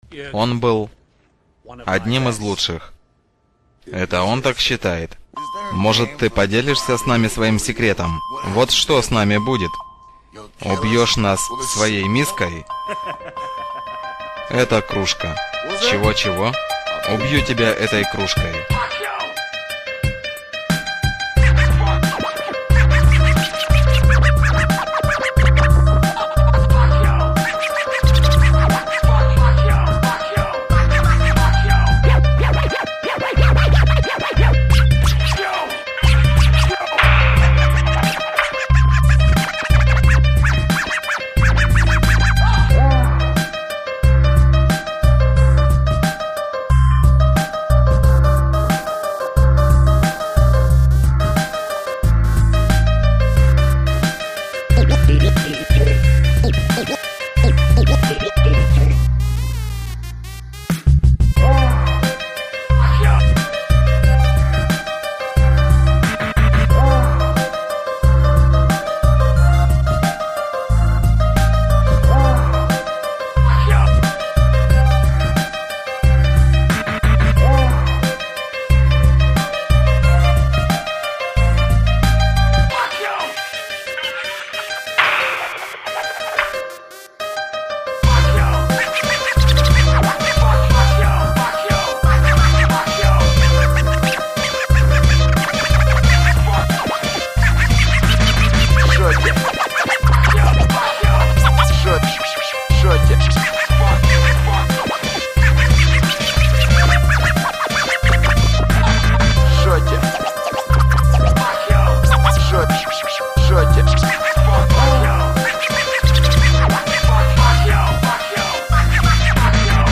Рэп
Трэк на баттл